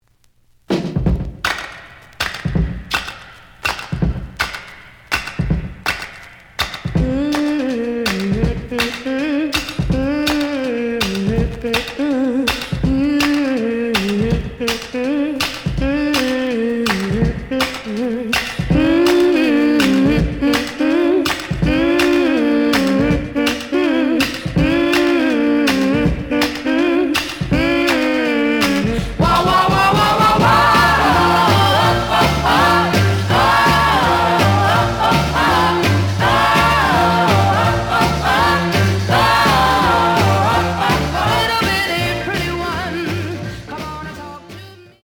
The audio sample is recorded from the actual item.
●Genre: Rhythm And Blues / Rock 'n' Roll
Slight noise on beginning of A side, but almost good.)